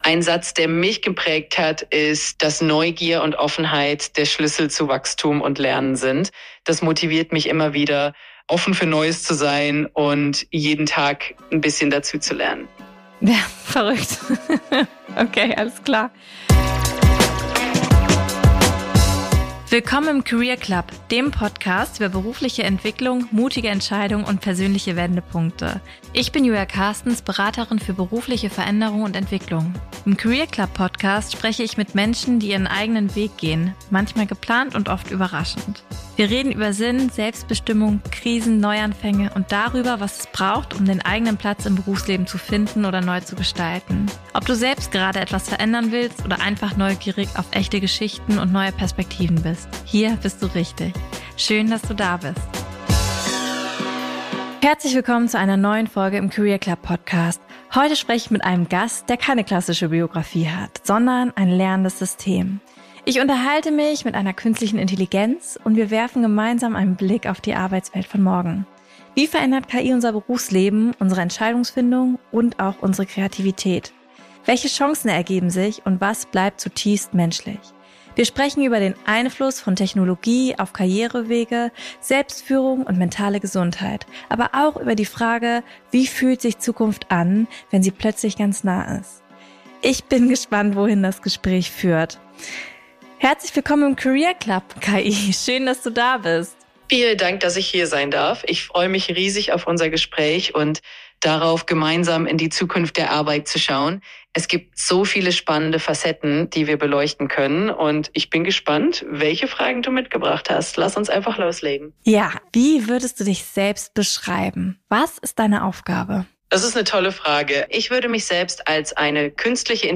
In dieser besonderen Episode des Career Club Podcasts spreche ich nicht mit einem Menschen, sondern mit einer Künstlichen Intelligenz.